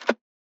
hovering.wav